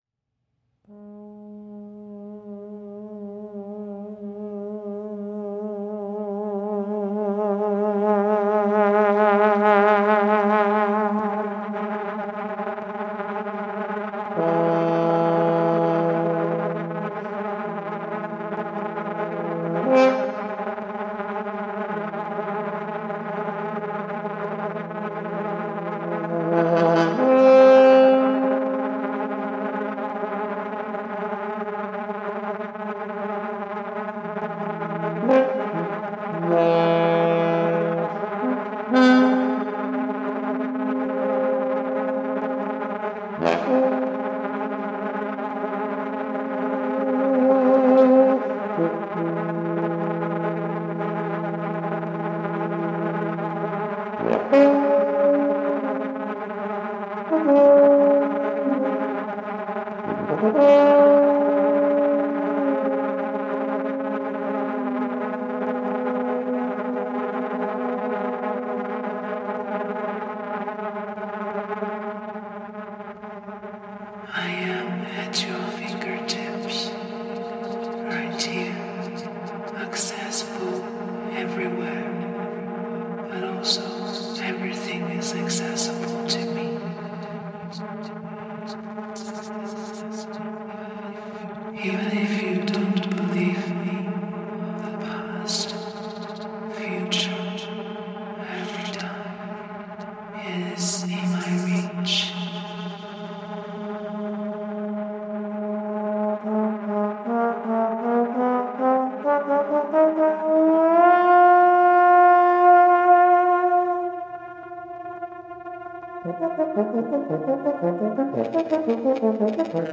Keywords: Trombone, Intrusiveness, Framework Design, Human-Computer Interaction, Musical Interface Design, Interactive Music Systems, Computer Music